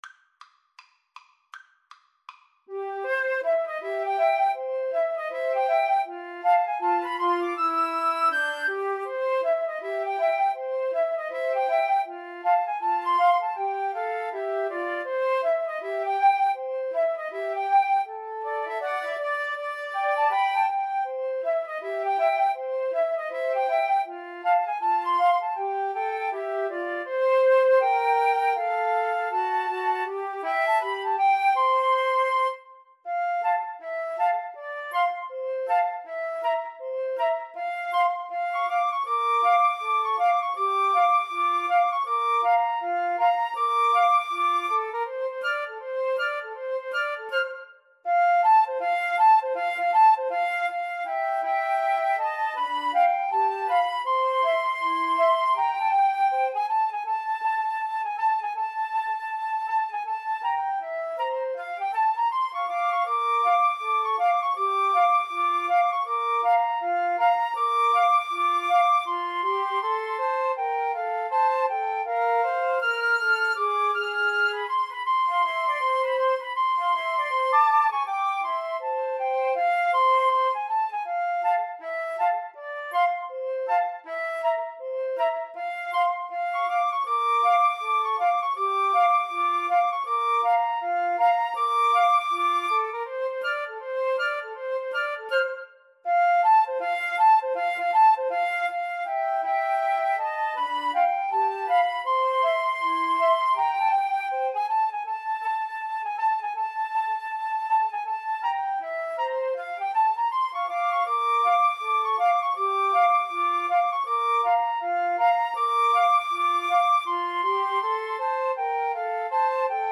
4/4 (View more 4/4 Music)
Flute Trio  (View more Intermediate Flute Trio Music)
Jazz (View more Jazz Flute Trio Music)